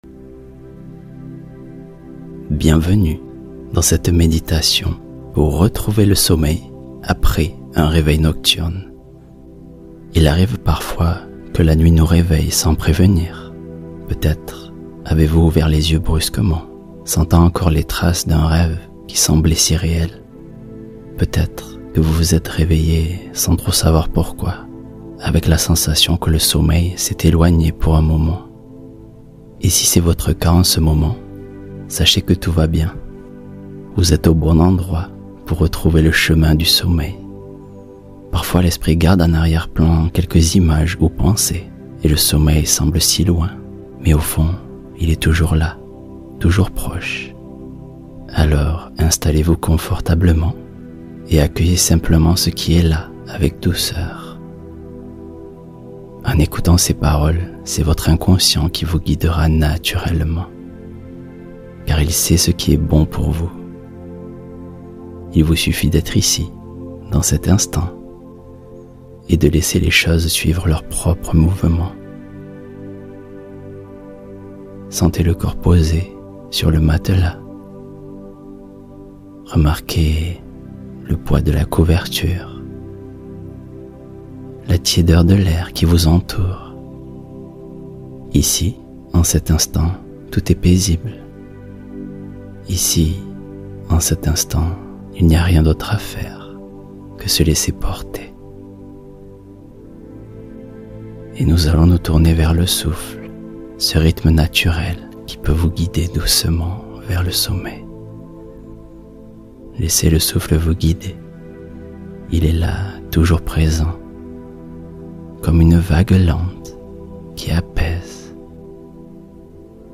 Réendors-toi en douceur : méditation guidée pour calmer les réveils nocturnes